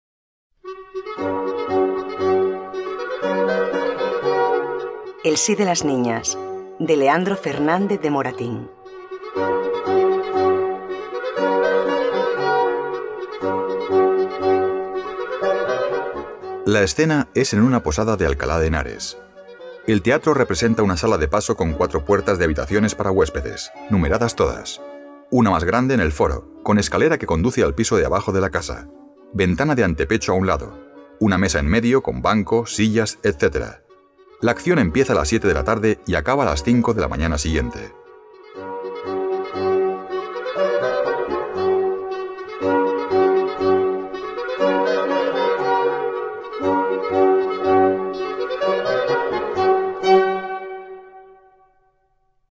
Comedia en 3 Actos